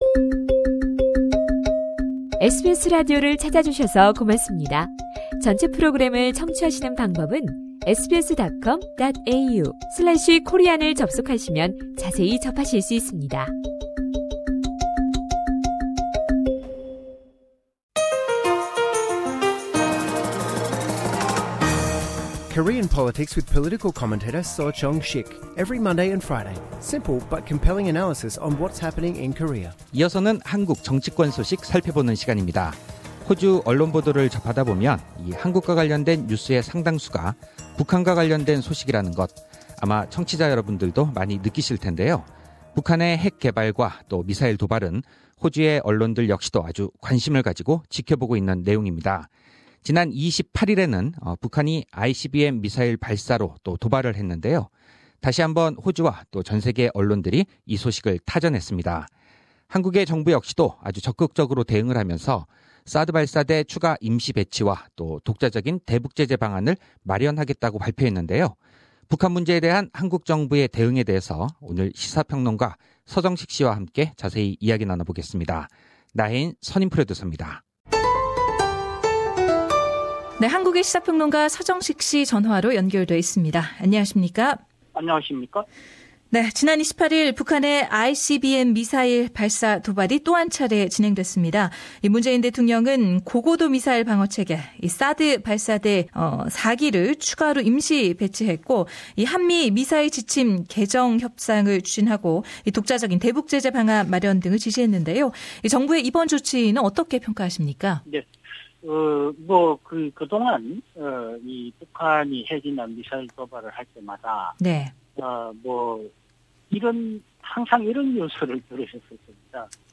상단의 팟 캐스트를 통해 전체 인터뷰를 들으실 수 있습니다.